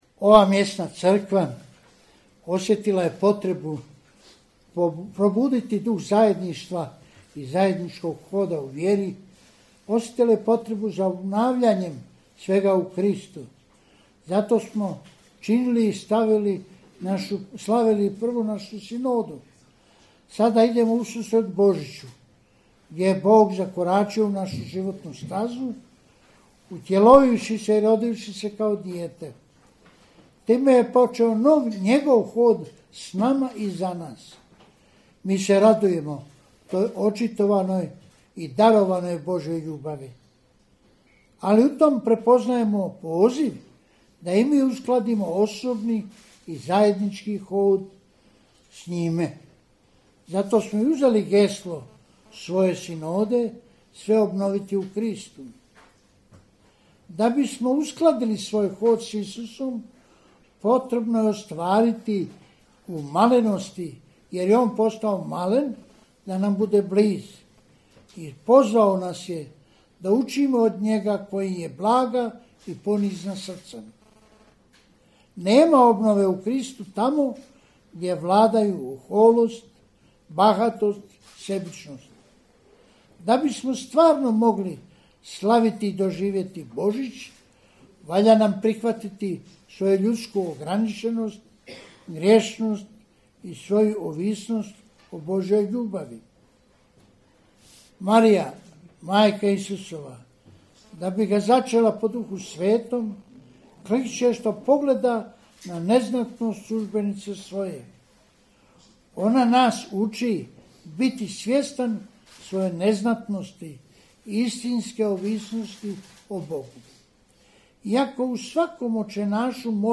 AUDIO: BOŽIĆNA PORUKA KARDINALA PULJIĆA I NADBISKUPA VUKŠIĆA